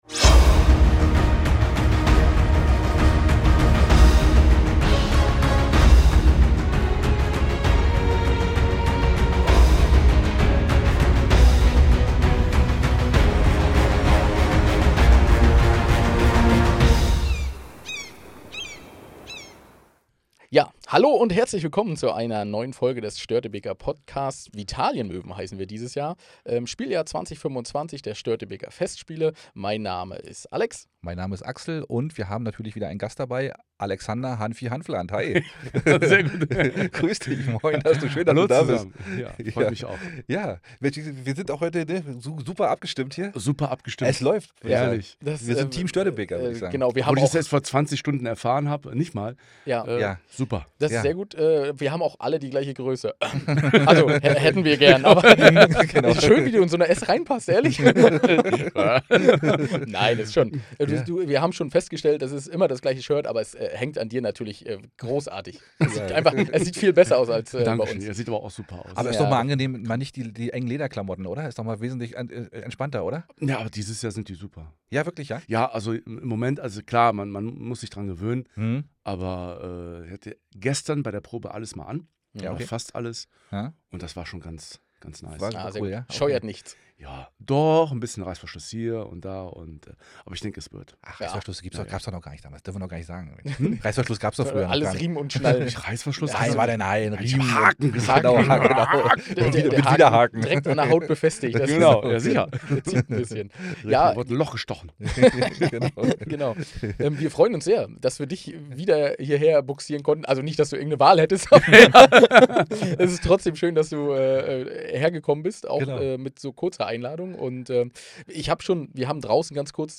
Ein ehrliches, witziges und herzliches Gespräch mit einem echten rheinländischen Original.